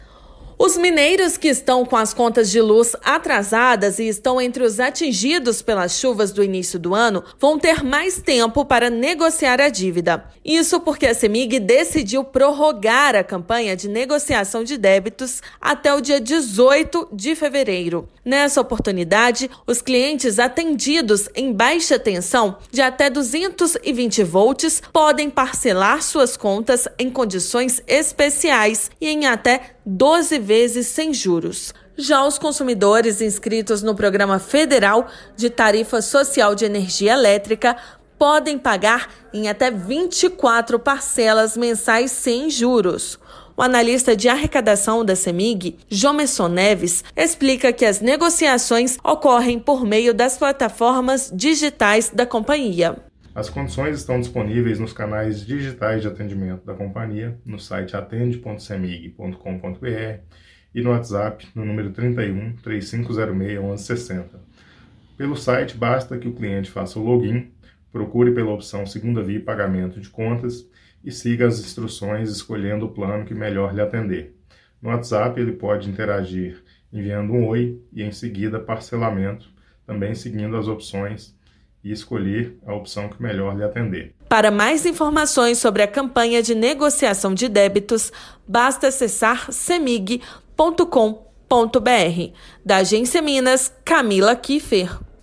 Clientes atingidos pelas chuvas do início do ano terão mais tempo para negociar as dívidas. Ouça matéria de rádio.